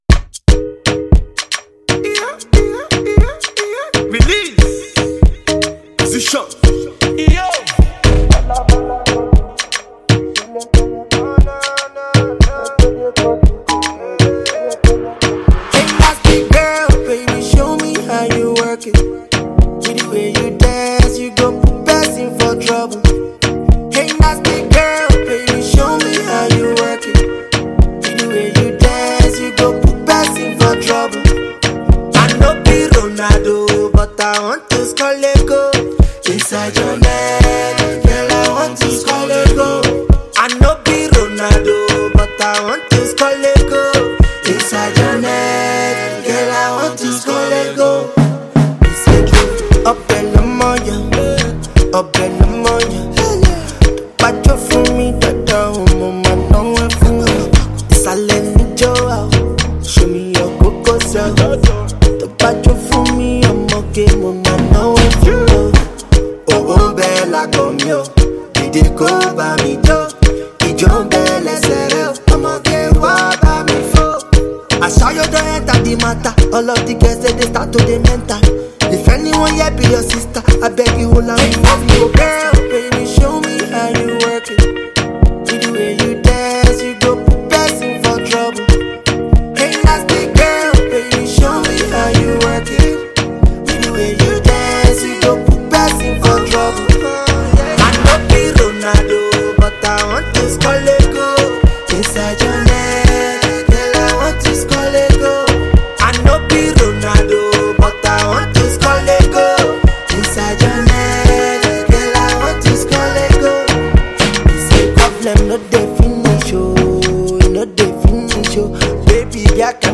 mid-tempo Afro-Pop sound